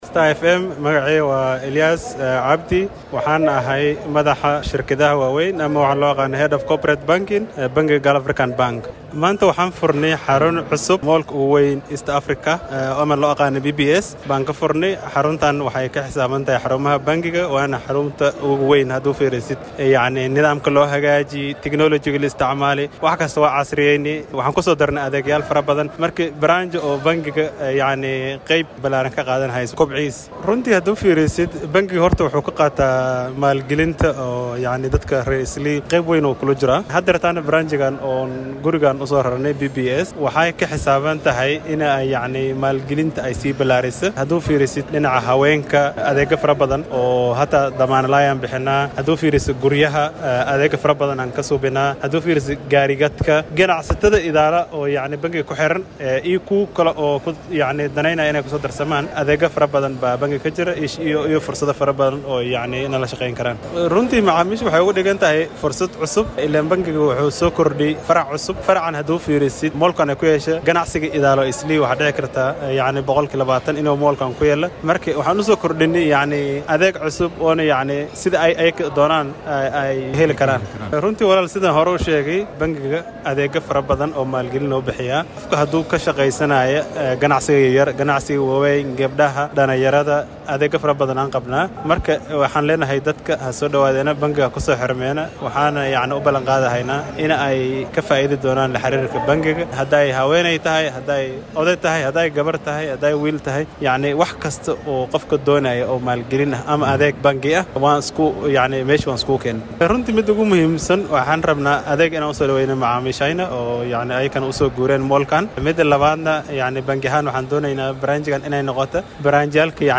Mid ka mid ah mas’uuliyiinta sare ee bangiga Gulf ayaa warbaahinta Star la hadlay.